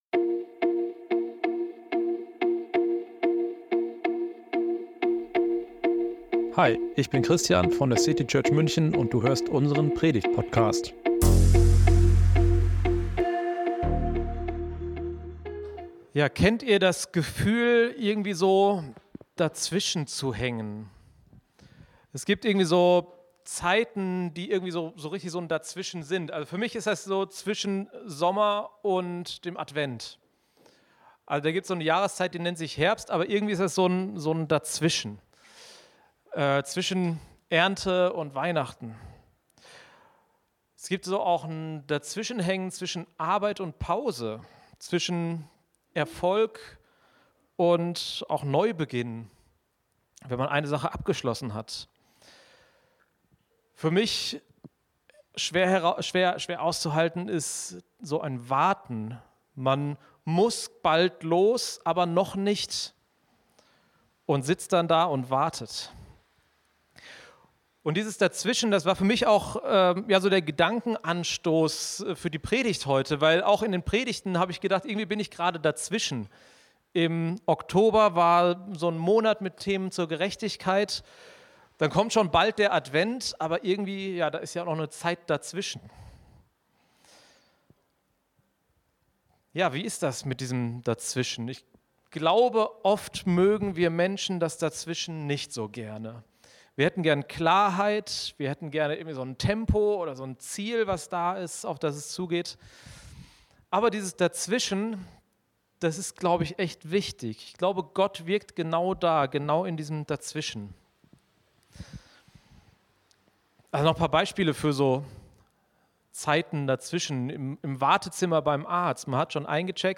Manchmal scheint das Leben stillzustehen – zwischen dem, was war, und dem, was kommt. Doch auch im Dazwischen wirkt Gott. In diesem Gottesdienst entdecken wir, wie wir vertrauen können, dass jede Zeit ihre Bedeutung hat.